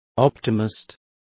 Complete with pronunciation of the translation of optimists.